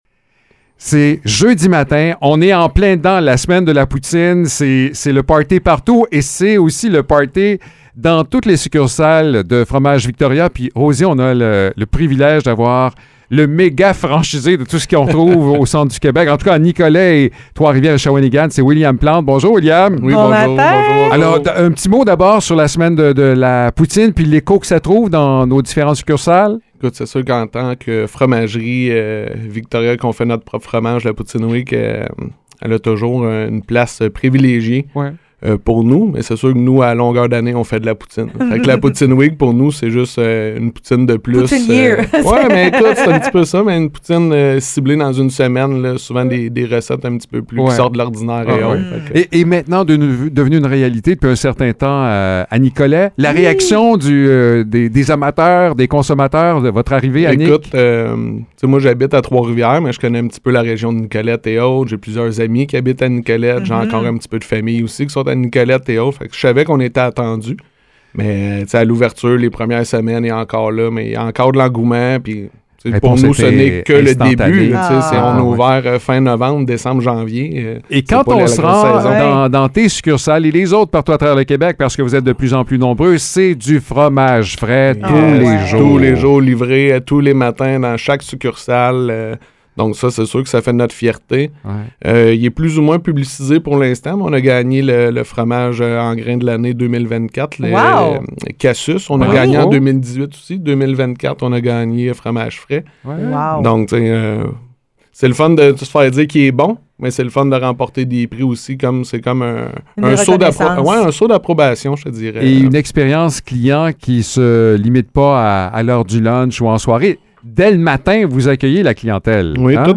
Entrevue avec la Fromagerie Victoria